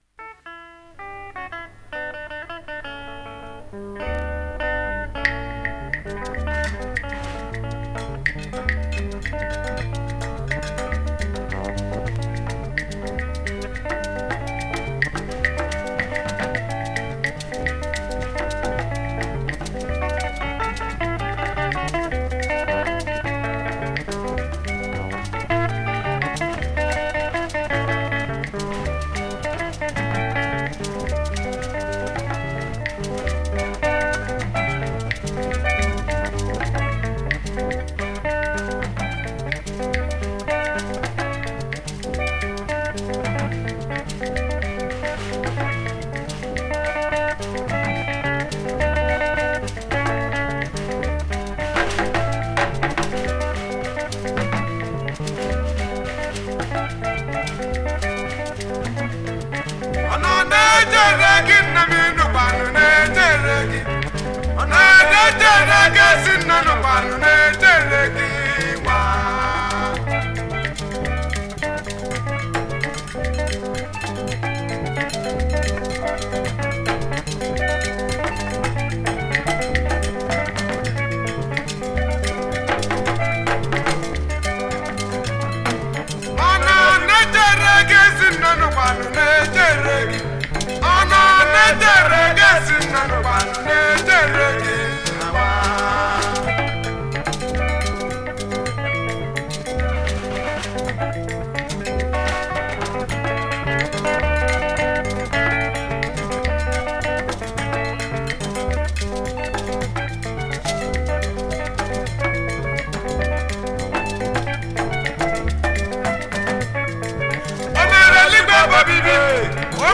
September 9, 2024 admin Highlife Music, Music 0
Nigerian Igbo highlife music icon